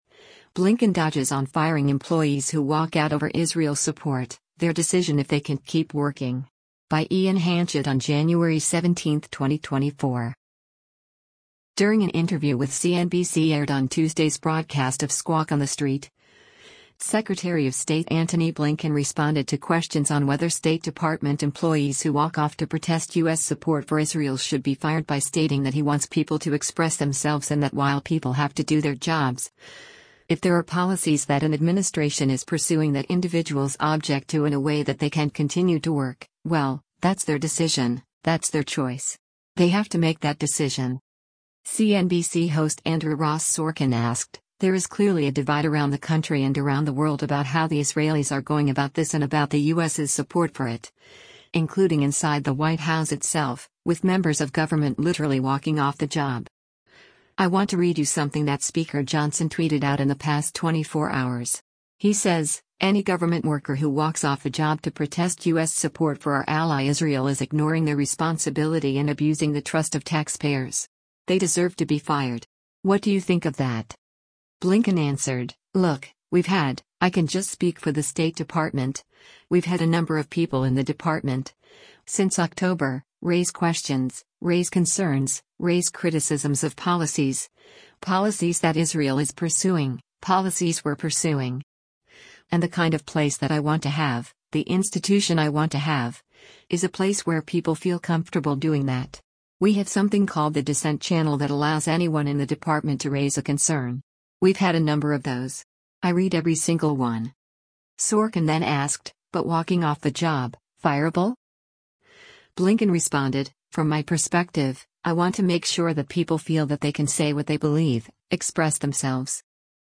During an interview with CNBC aired on Tuesday’s broadcast of “Squawk on the Street,” Secretary of State Antony Blinken responded to questions on whether State Department employees who walk off to protest U.S. support for Israel should be fired by stating that he wants people to express themselves and that while people have to do their jobs, “If there are policies that an administration is pursuing that individuals object to in a way that they can’t continue to work, well, that’s their decision, that’s their choice. They have to make that decision.”